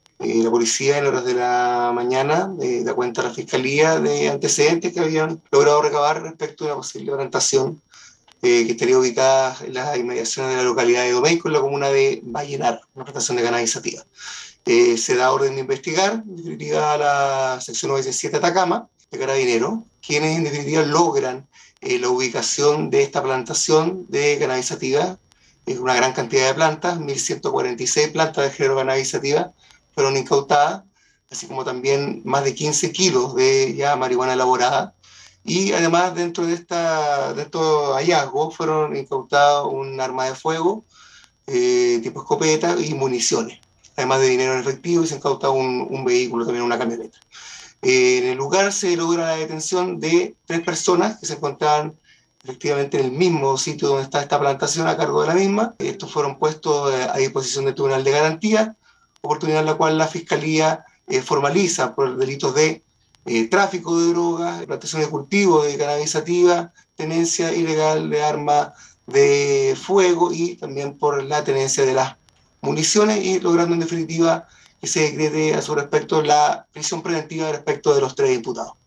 CUÑA-FISCAL-LUIS-ZEPEDA.mp3